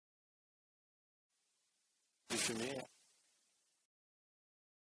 fumier_mot.mp3